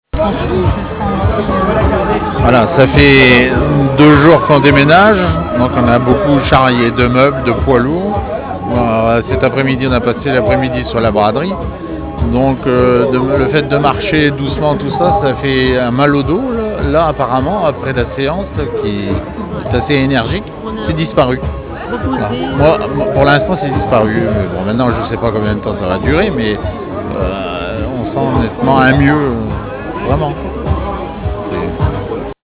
Massage sur Braderie de Lille 2010
Ci-joint plusieurs témoignages d'utilisateurs comblés.